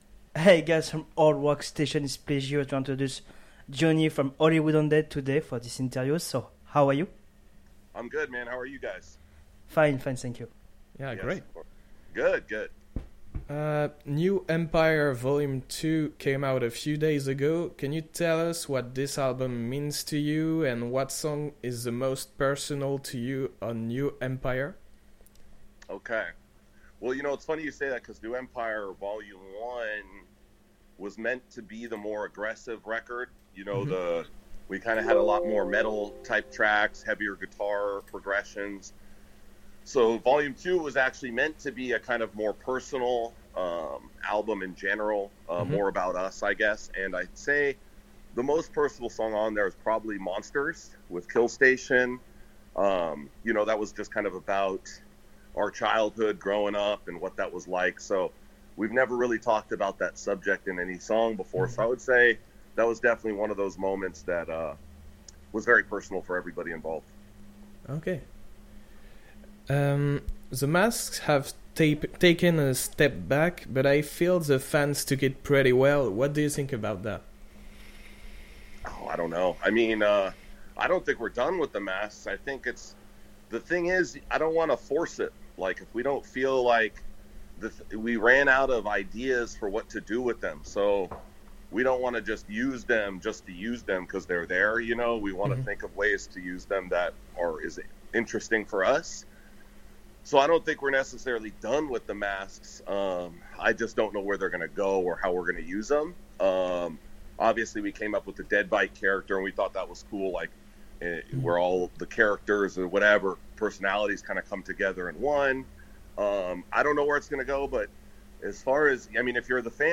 Interview in english